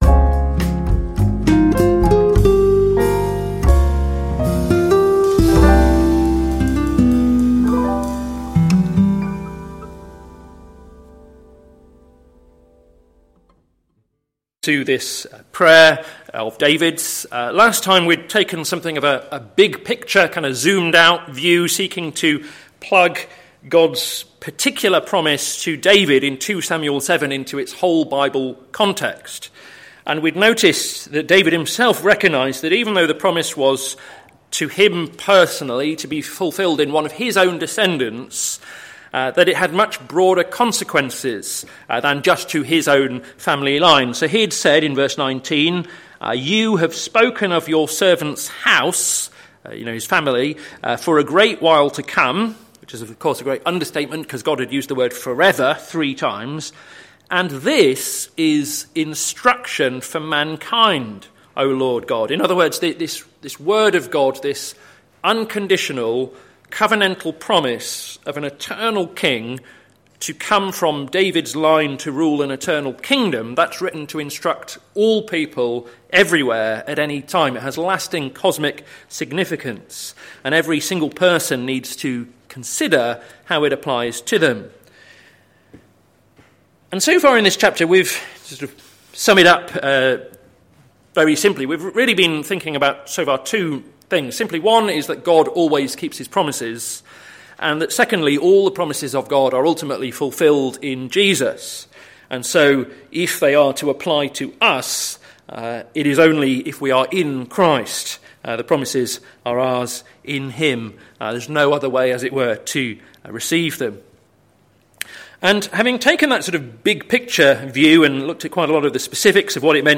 Sermon Series - May the Lord establish His Word - plfc (Pound Lane Free Church, Isleham, Cambridgeshire)